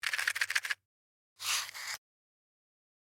Match Box Shake and Open
SFX
yt_PTmZ7JORLL4_match_box_shake_and_open.mp3